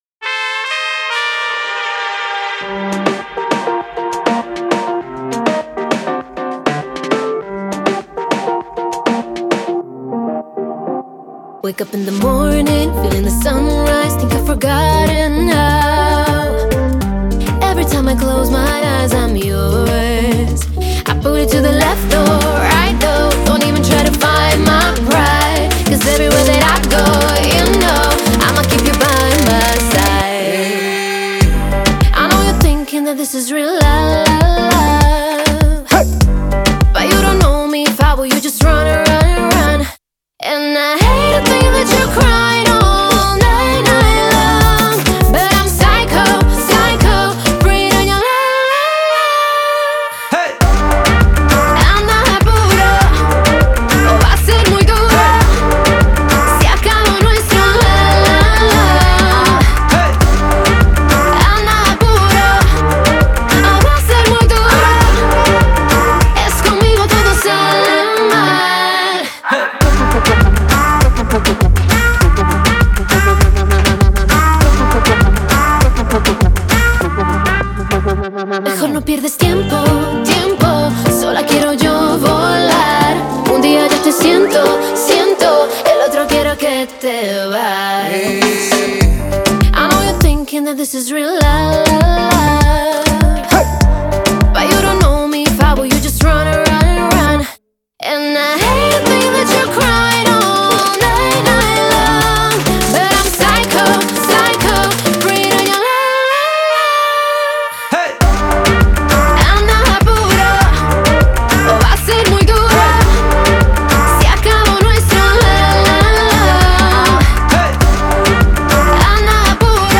это энергичная поп-песня